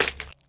gibhit2.wav